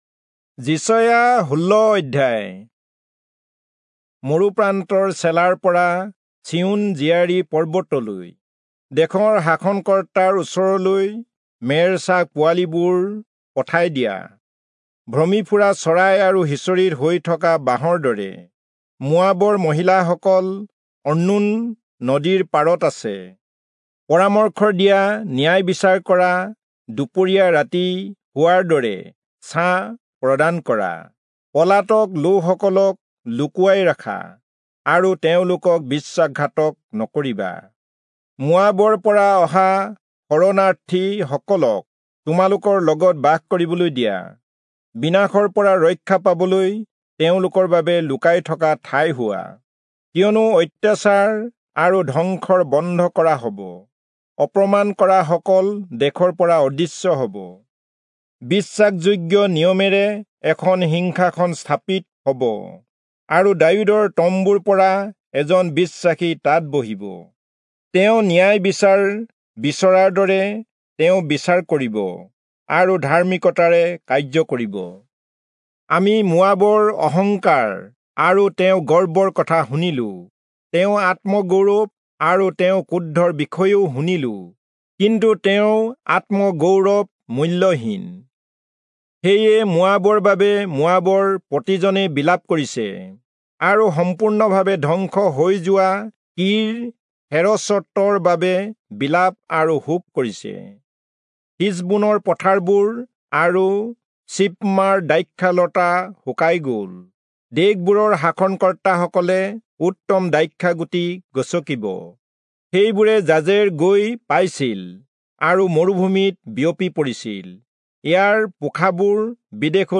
Assamese Audio Bible - Isaiah 14 in Bnv bible version